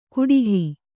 ♪ kuḍihi